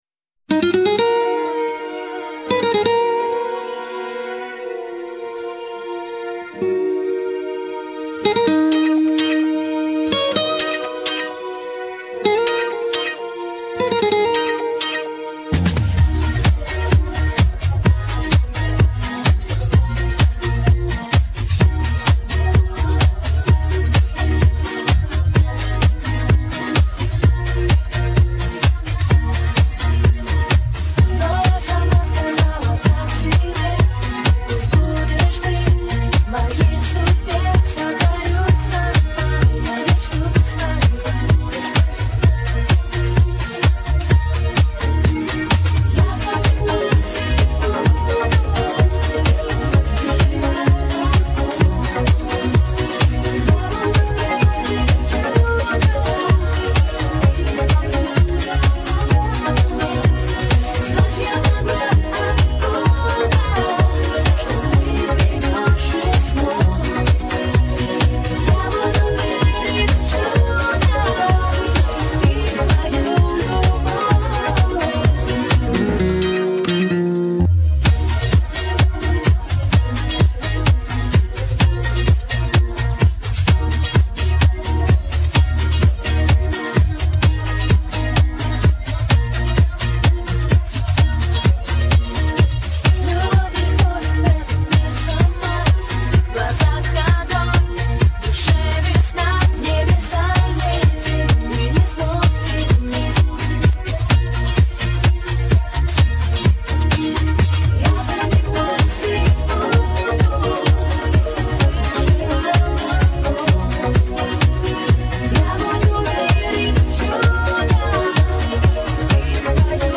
Качество:Задавка